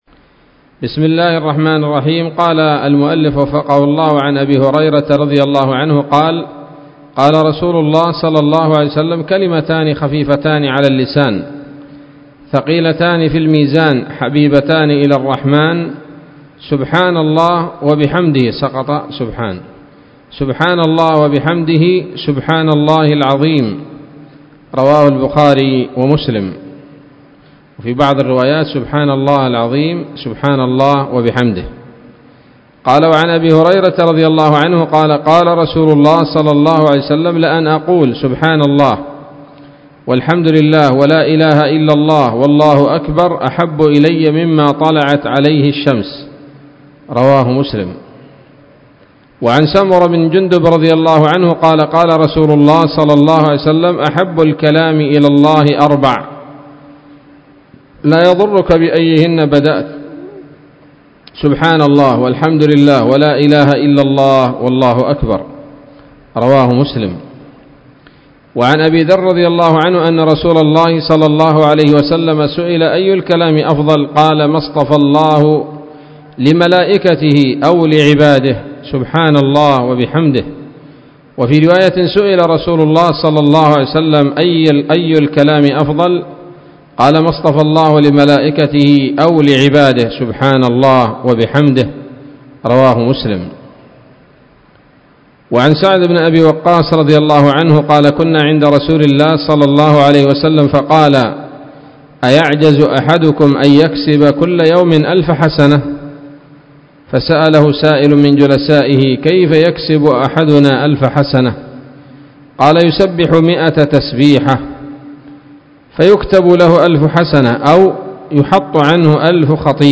الدرس السابع من رياض الأبرار من صحيح الأذكار